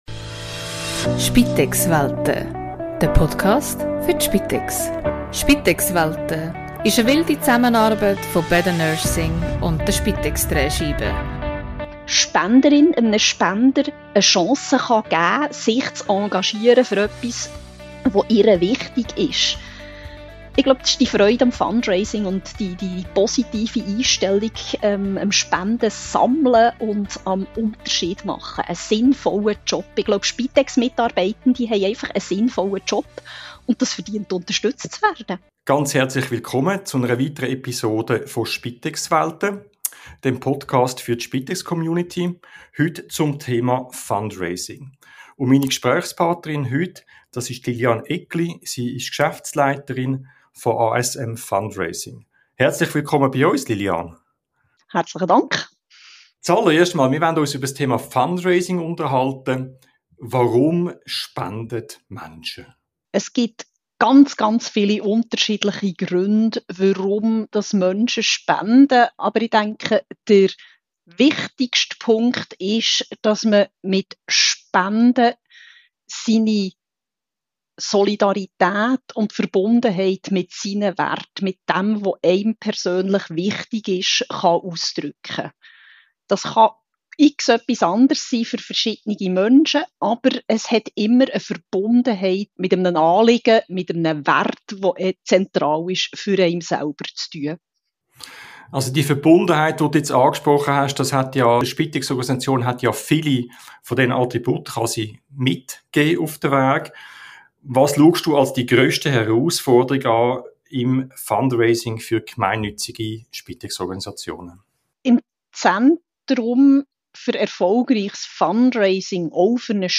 In diesem Gespräch geht es um die Professionalisierung des Fundraisings in Spitex-Organisationen.